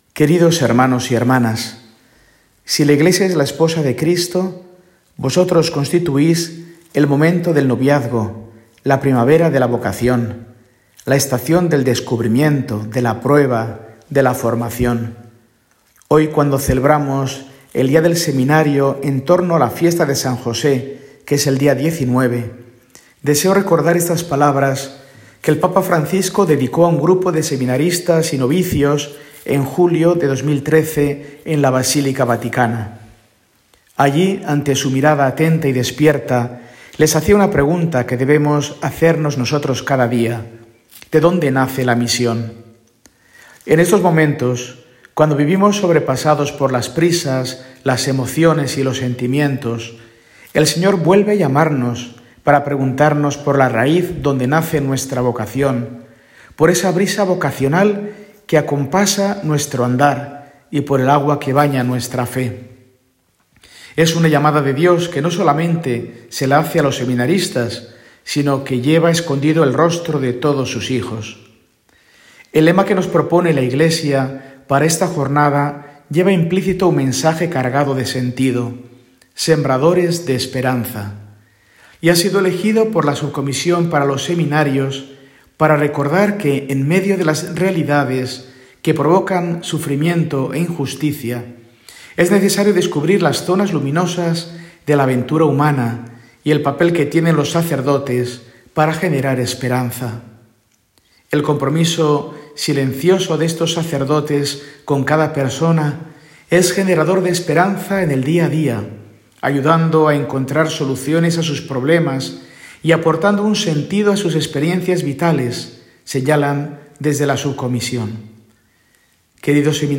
Mensaje semanal de Mons. Mario Iceta Gavicagogeascoa, arzobispo de Burgos, para el domingo, 16 de marzo de 2025, II de Cuaresma y Día del Seminario